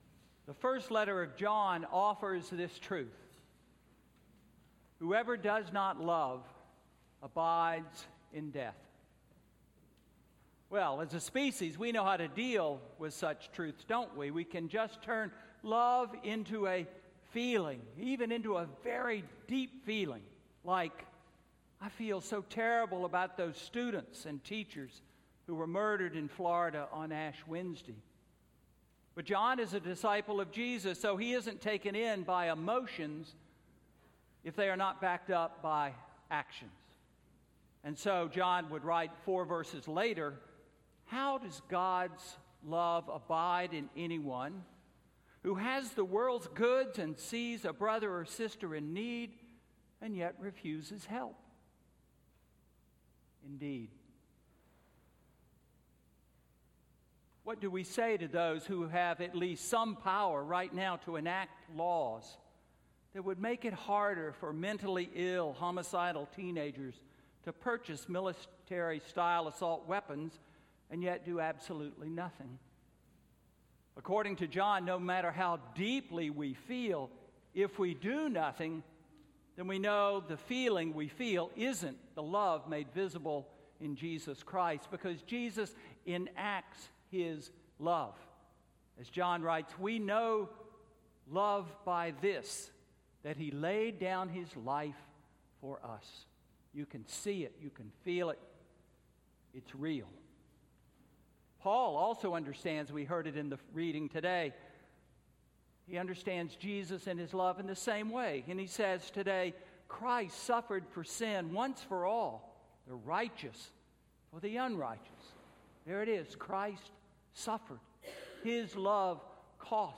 Sermon–February 18, 2018